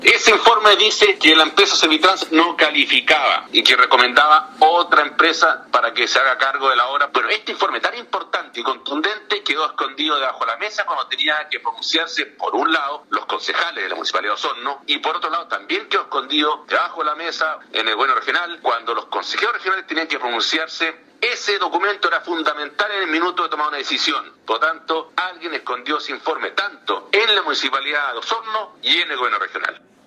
Corría el año 2016 cuando -en su calidad de Consejero Regional- el ahora diputado acusaba que alguien había escondido el Informe técnico de una empresa independiente, POCH, que acreditaba la falta de experiencia de Servitrans en obras de este tipo, tanto en el municipio como en el Gobierno Regional.